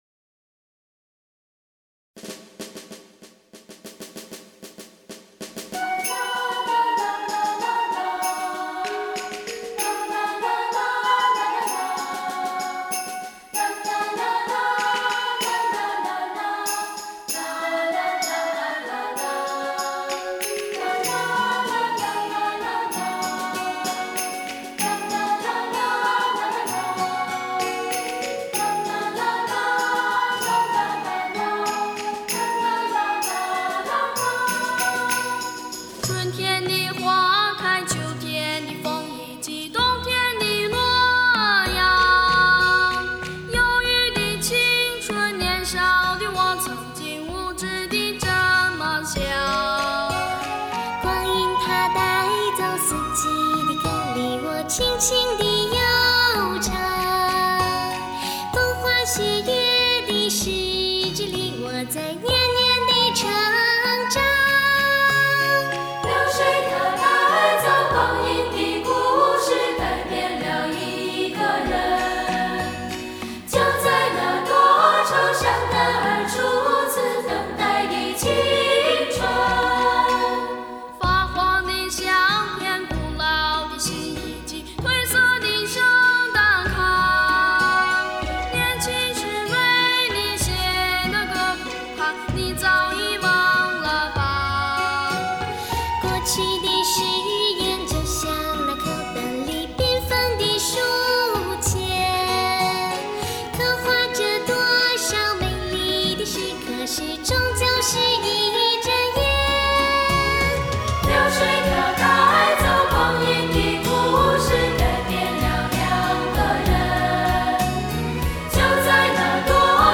幸福温暖的合声，满足喜爱音乐的耳朵，
以清澈优美的和声，征服国内外乐迷，